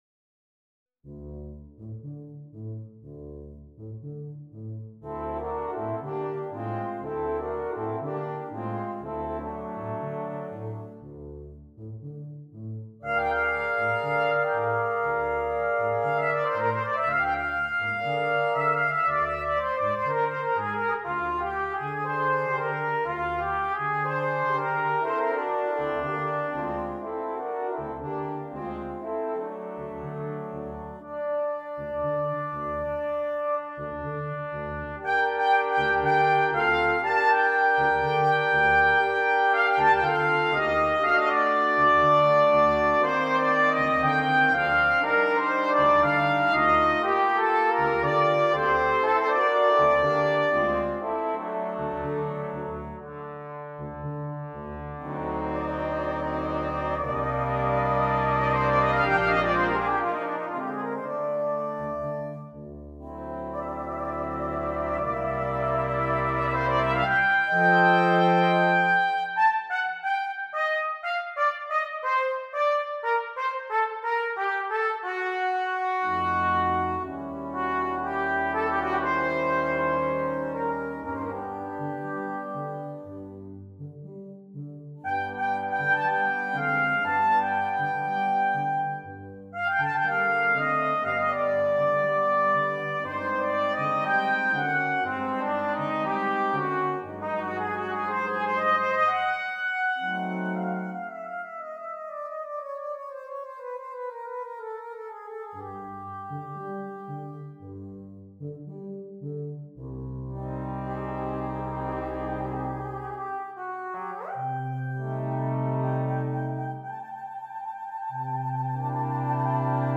Brass Quintet - optional Percussion